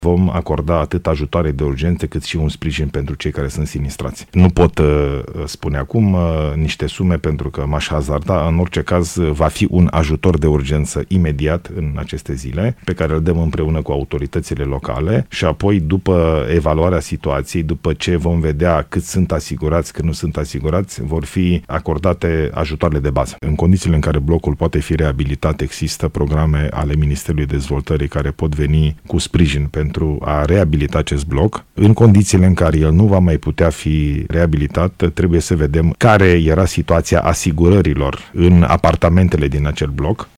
Ilie Bolojan a spus la postul public de radio că este vorba despre persoane cu arsuri serioase și că ministrul Sănătății i-a confirmat că aceștia vor primi îngrijiri medicale în străinătate.
Prim-ministrul a vorbit și despre ajutoarele pe care le vor primi din partea Guvernului cei afectați de explozie